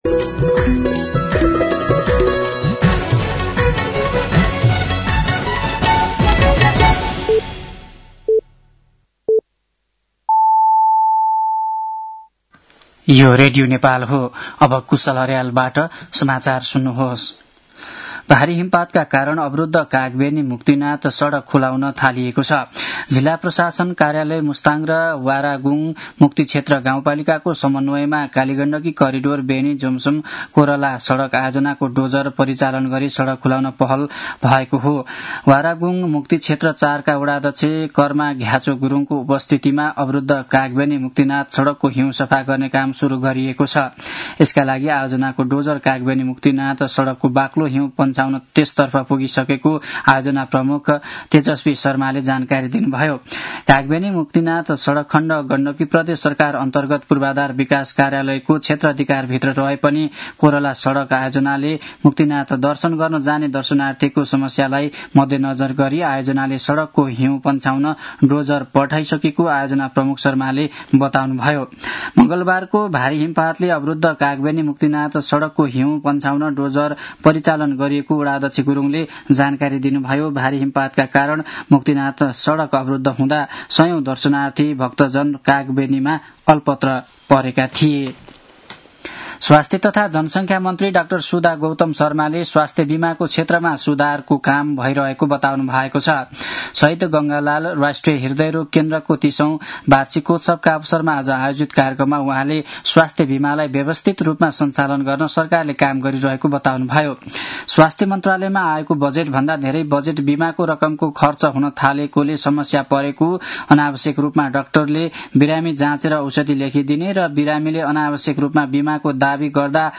साँझ ५ बजेको नेपाली समाचार : १५ माघ , २०८२
5.-pm-nepali-news-1-11.mp3